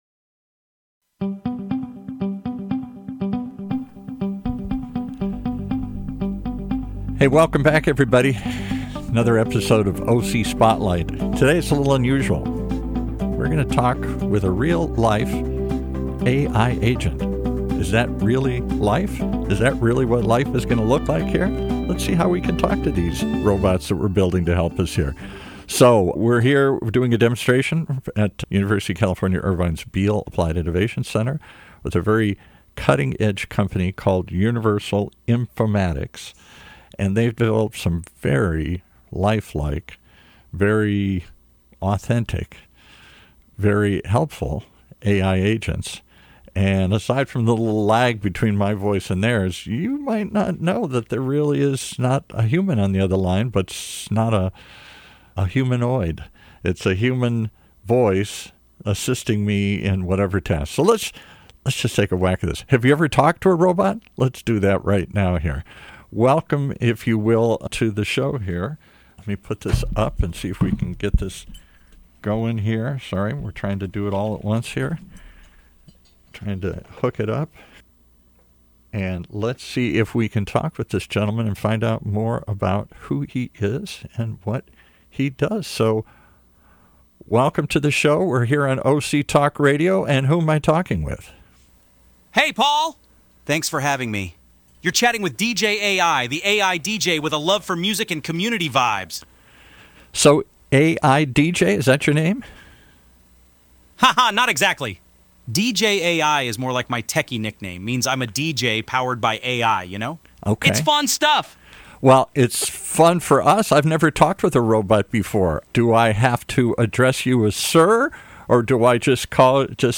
Having a conversation with a real, live robot.